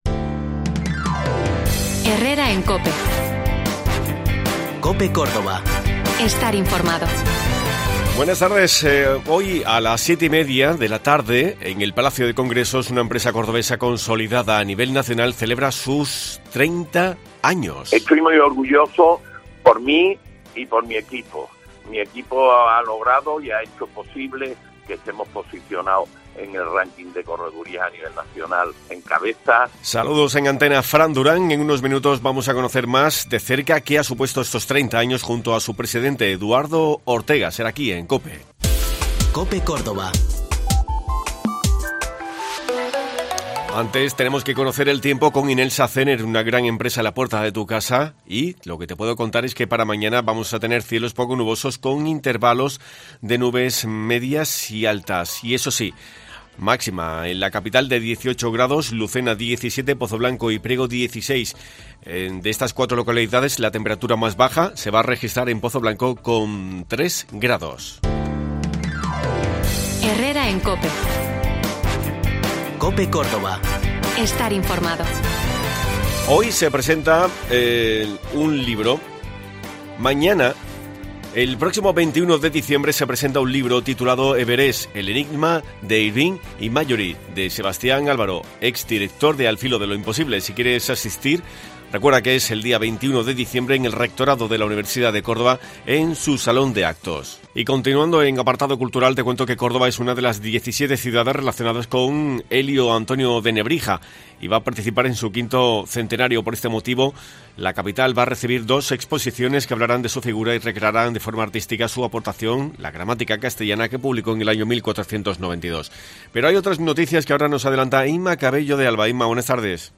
Un repaso diario a la actualidad y a los temas que te preocupan.